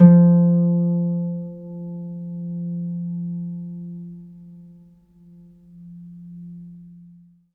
HARP GN3 SUS.wav